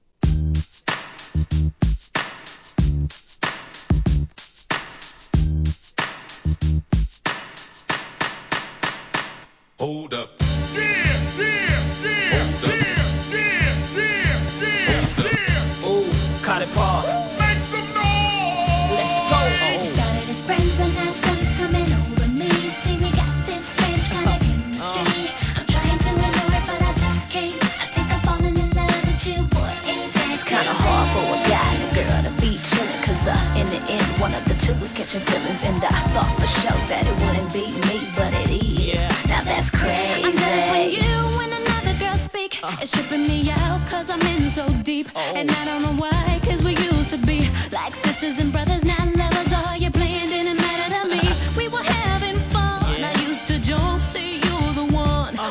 HIP HOP/R&B
PARTYには欠かせない煽りサンプリング・イントロでシリーズ狙い通りのREMIXに!!